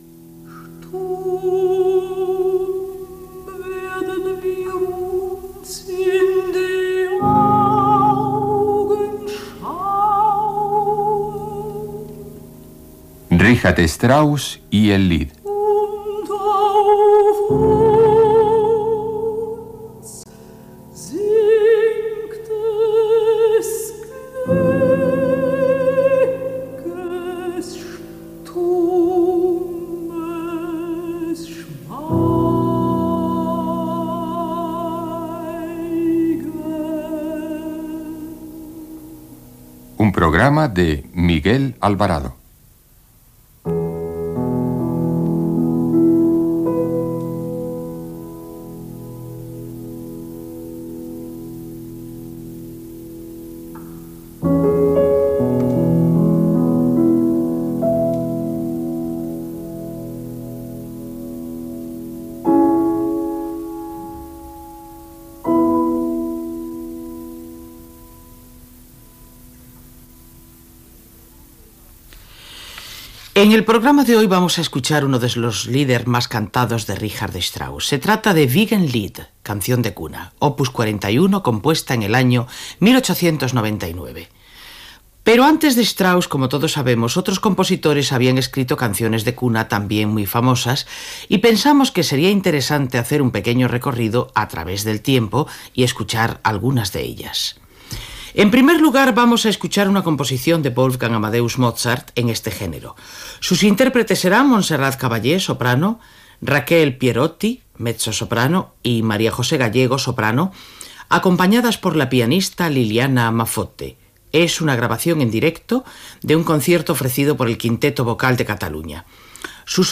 Careta del programa, presentació i espai dedicat a les cançons de bressol
Musical